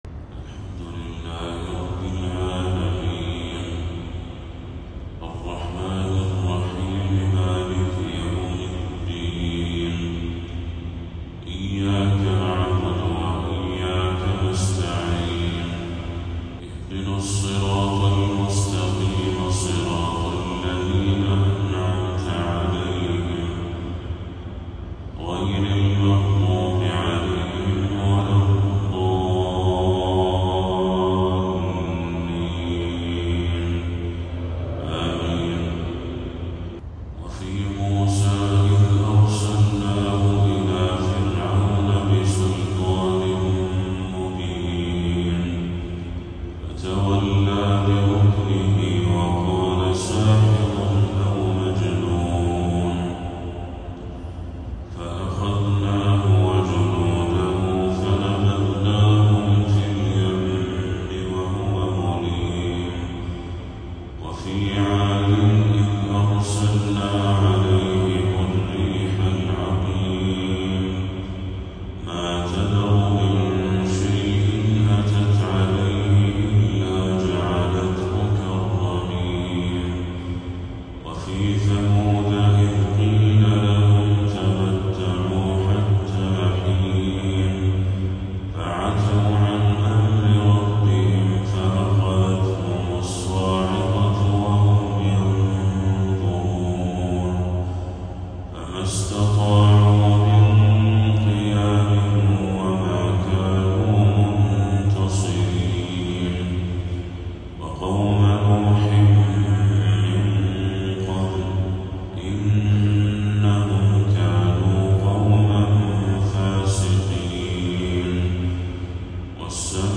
تلاوة خاشعة لخواتيم سورتي الذاريات والحاقة للشيخ بدر التركي | فجر 6 ربيع الأول 1446هـ > 1446هـ > تلاوات الشيخ بدر التركي > المزيد - تلاوات الحرمين